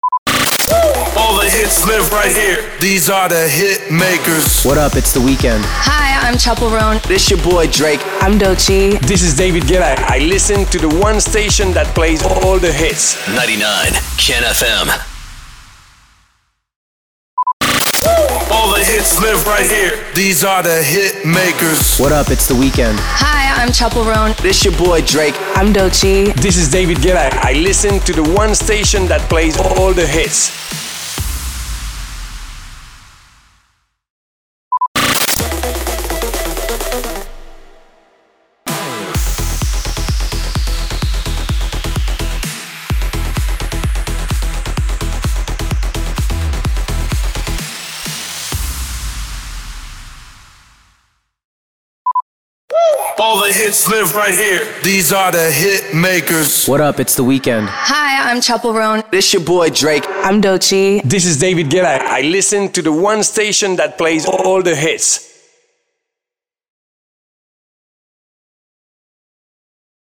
697 – SWEEPER – ARTIST MONTAGE
697-SWEEPER-ARTIST-MONTAGE.mp3